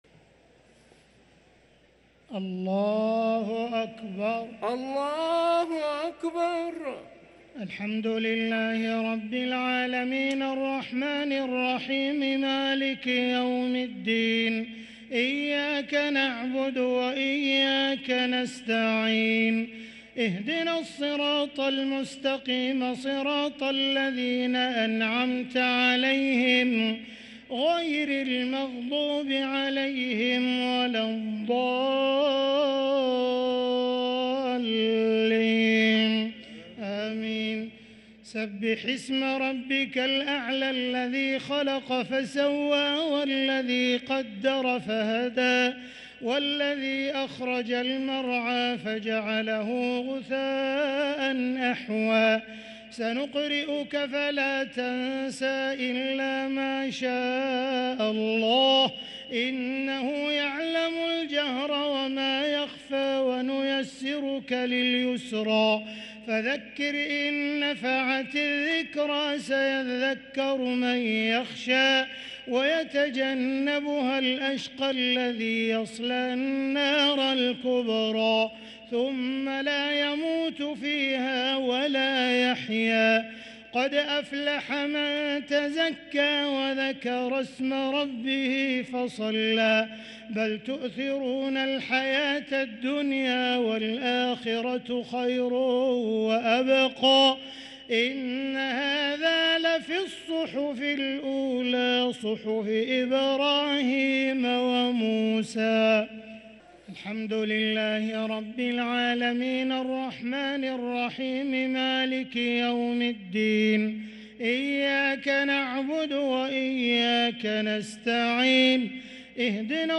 الشفع و الوتر ليلة 23 رمضان 1444هـ | Witr 23 st night Ramadan 1444H > تراويح الحرم المكي عام 1444 🕋 > التراويح - تلاوات الحرمين